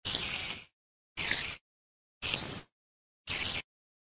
Все звуки оригинальные и взяты прямиком из игры.
Объединенные звуки
Шипение пауков